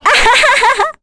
Ophelia-vox-Happy3.wav